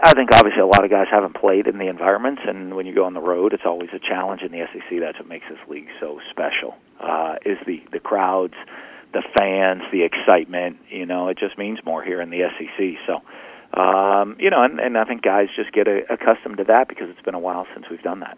Mullen-Interview.wav